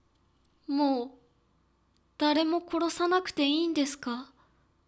ボイス
中性